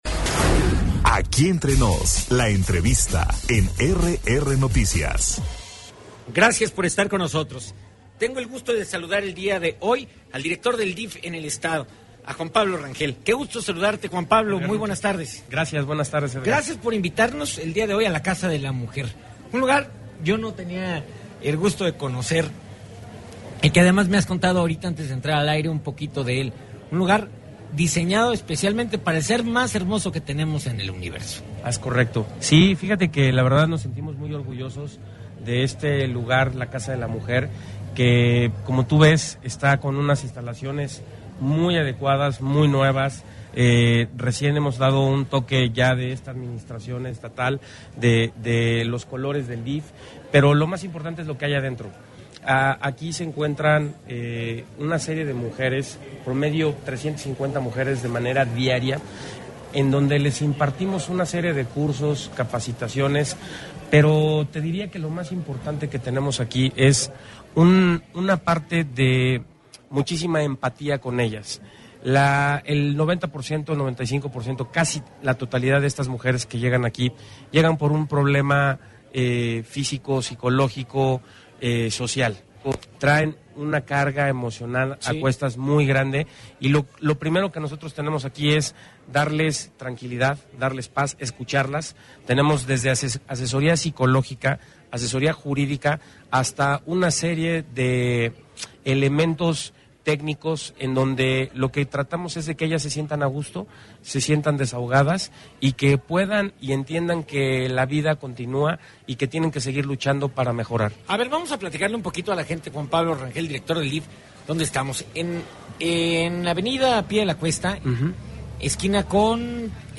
En entrevista el director del Sistema Estatal del DIF, Juan Pablo Rangel